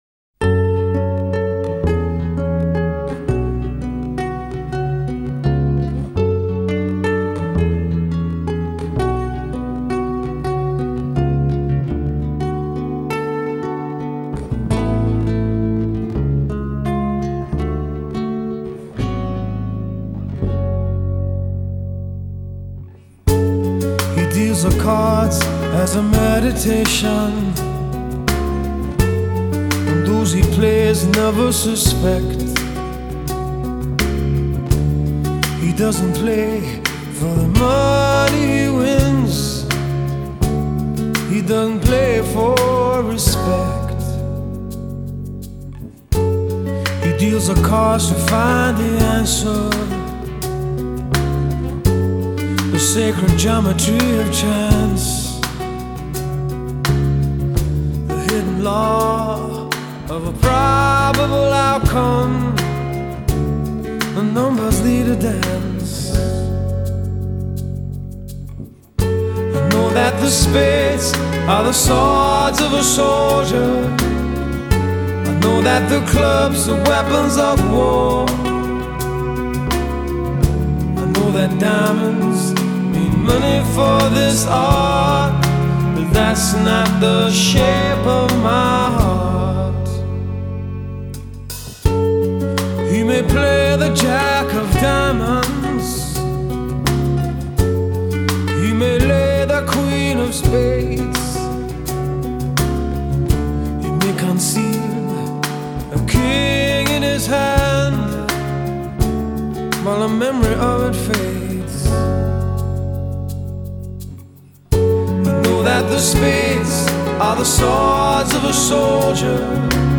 зарубежные медляки
спокойные песни , медленная музыка